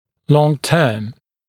[ˌlɔŋ’tɜːm][‘лонˌтё:м]длительный, долгосрочный